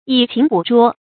以勤補拙 注音： ㄧˇ ㄑㄧㄣˊ ㄅㄨˇ ㄓㄨㄛ 讀音讀法： 意思解釋： 用勤奮補救笨拙，含有自謙之意。